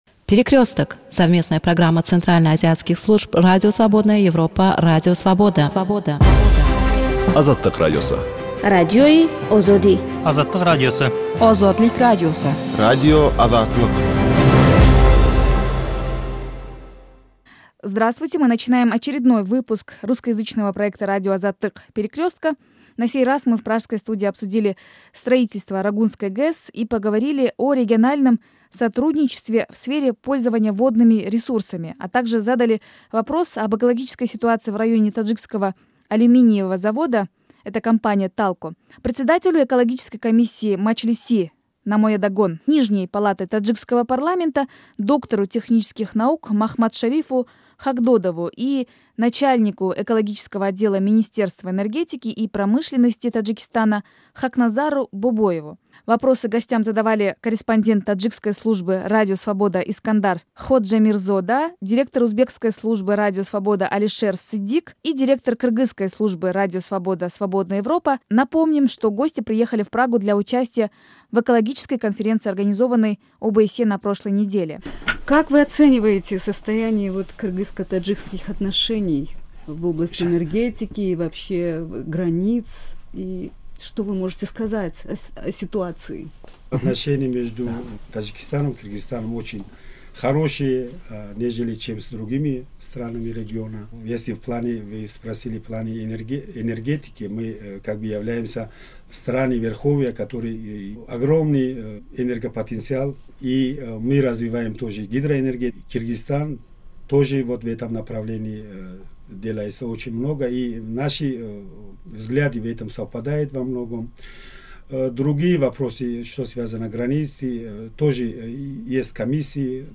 Этот выпуск «Перекрестка» посвящен строительству Рогунской ГЭС, региональному сотрудничеству в сфере пользования водными ресурсами в регионе, и экологической ситуации в районе таджикского алюминиевого завода (компания ТАЛКО). В дискуссии принимали учатие председатель экологической комиссии нижней палаты таджикского парламента